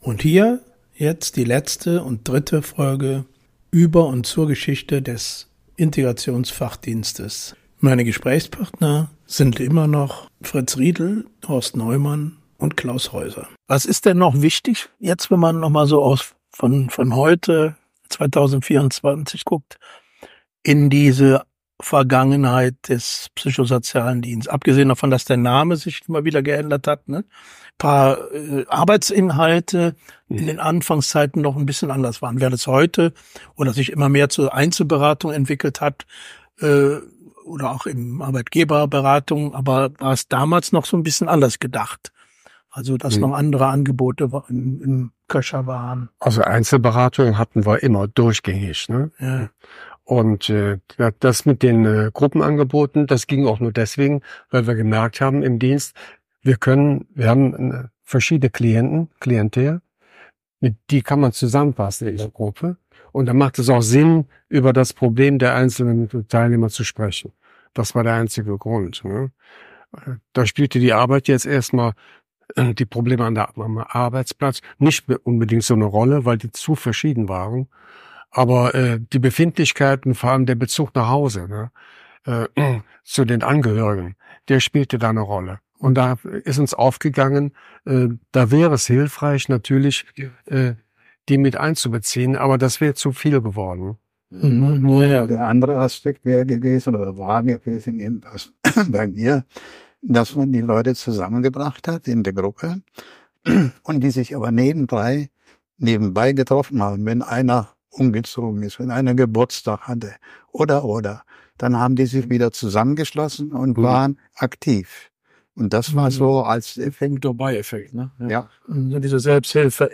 Interesse auch der Arbeitgeber:innen 3. Folge: Fallerzählungen; Anekdoten; Musikerinnerungen Musik Jazz
piano
bass
drums Mehr